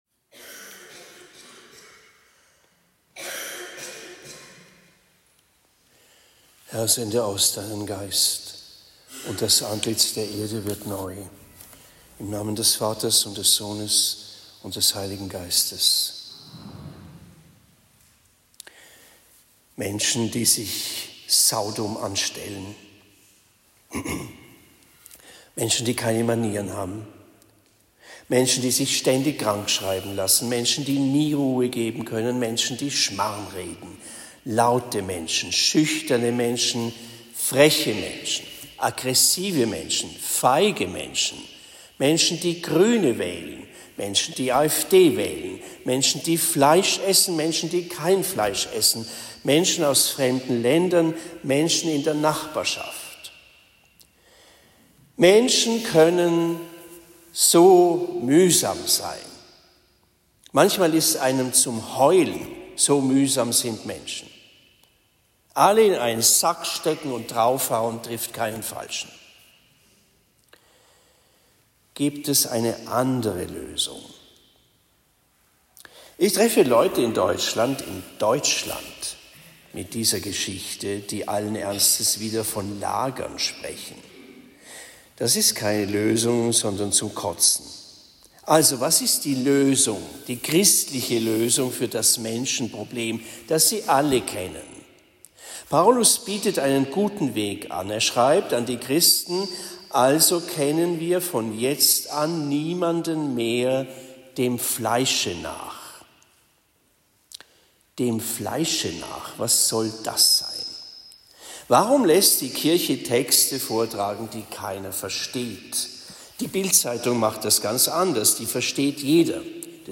Predigte in Bischbrunn Heiligste-Dreifaltigkeit am 23. Juni 2024